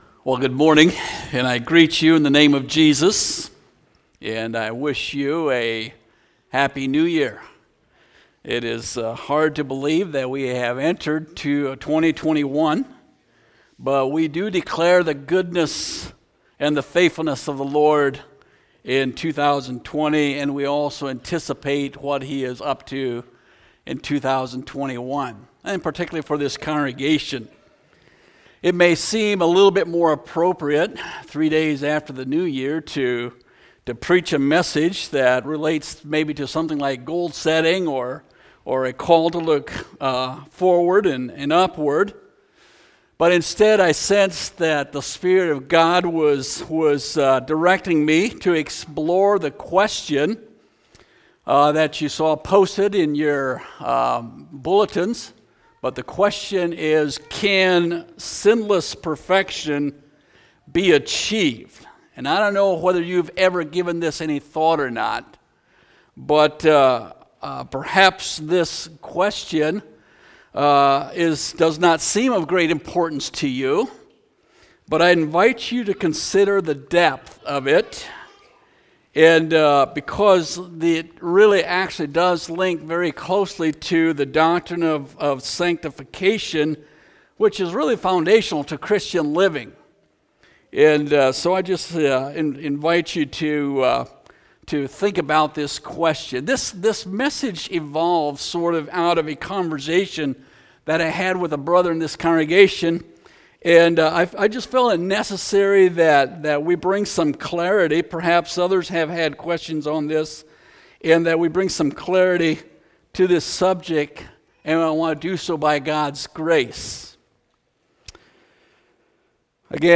Home Sermons Can Sinless Perfection Be Achieved?